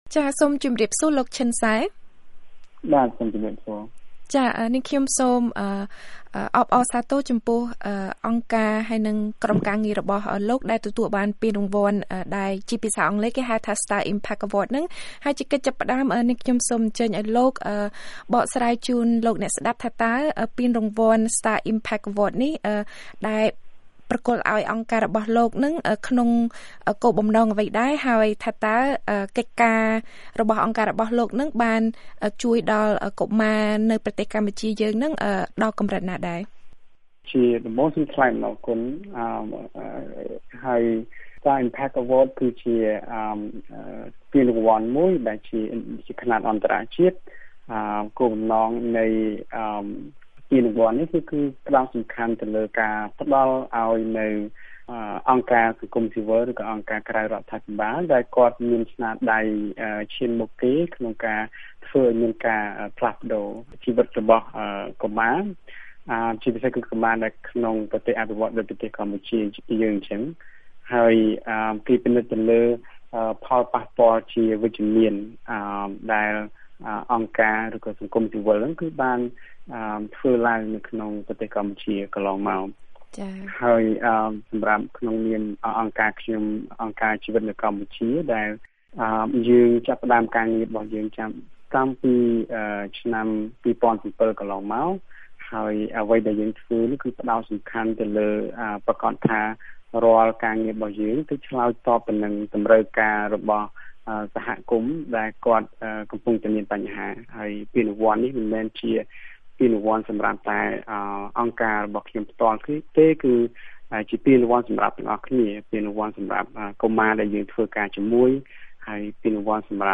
បទសម្ភាសន៍